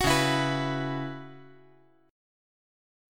Dbm11 Chord
Listen to Dbm11 strummed